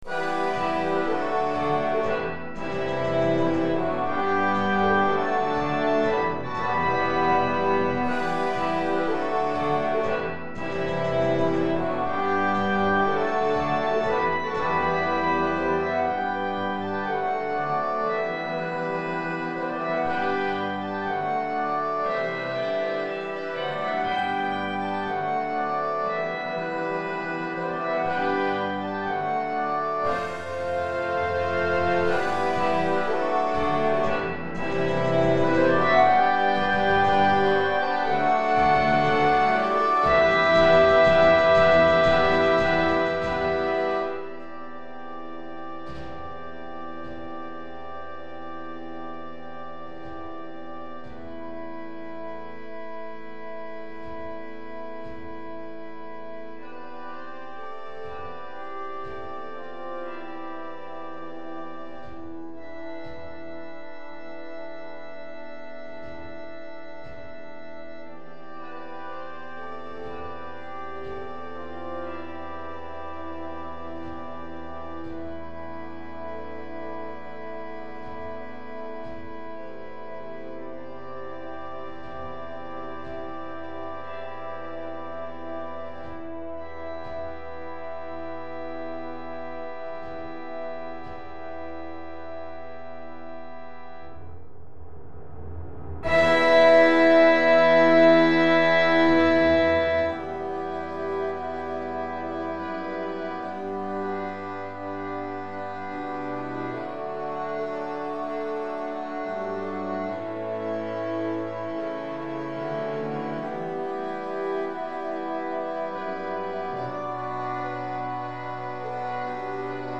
Orchestre D'Harmonie